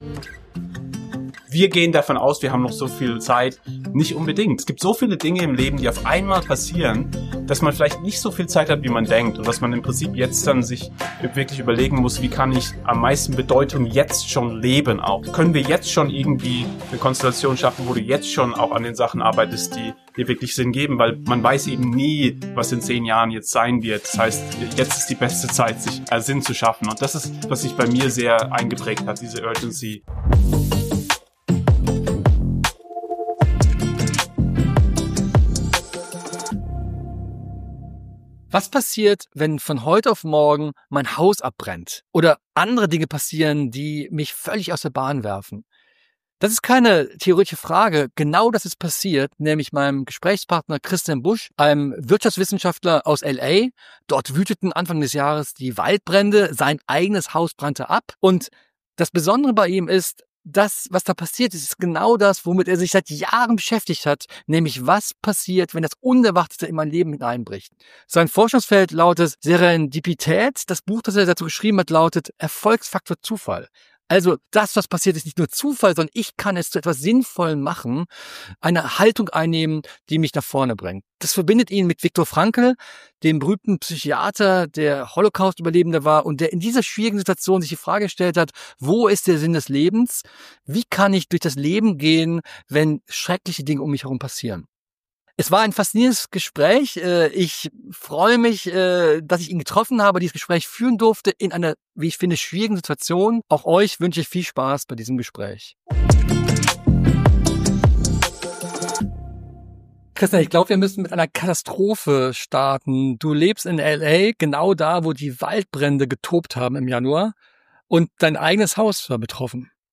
Im Interview erklärt er, welche Haltung er in Krisen einnimmt, welche Erkenntnisse er aus seiner Forschung zieht und warum kleine Momente der Hoffnung entscheidend sind.